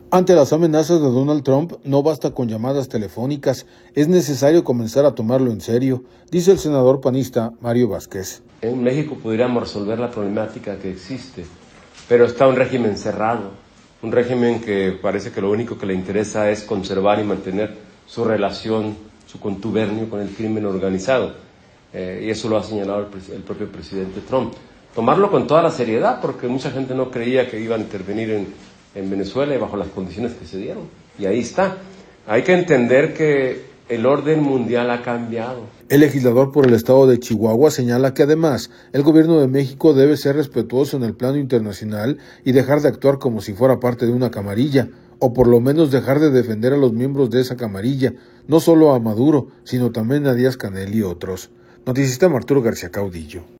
Ante las amenazas de Donald Trump no basta con llamadas telefónicas, es necesario comenzar a tomarlo en serio, dice el senador panista, Mario Vázquez.